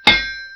anvilhammer.ogg